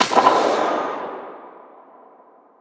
JackHammer_far_02.wav